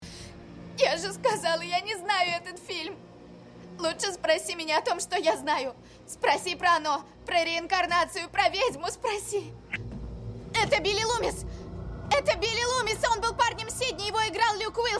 На этой странице собраны дикторские голоса, которые мы можем записать для вашего проекта.
Специализируется на эмоциональном озвучивании текстов